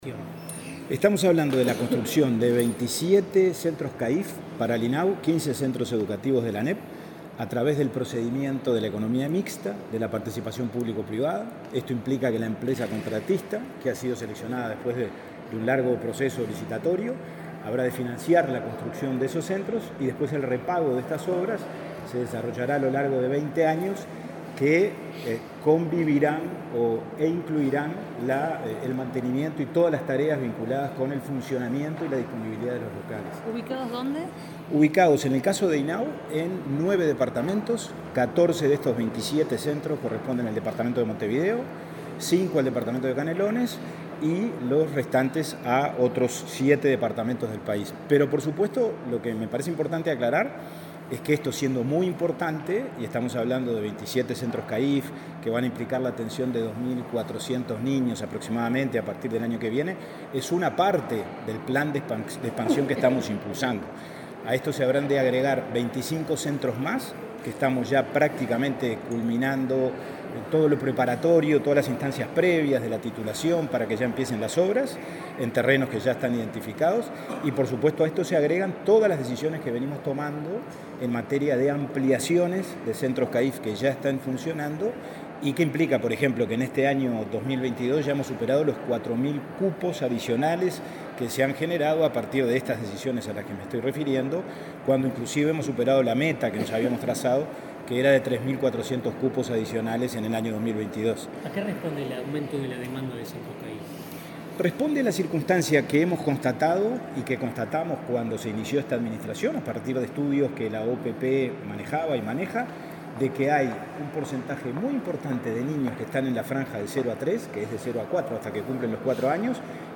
Declaraciones del presidente del INAU, Pablo Abdala
El presidente del Instituto del Niño y el Adolescente del Uruguay (INAU), Pablo Abdala, dialogó con la prensa antes de participar del acto de firma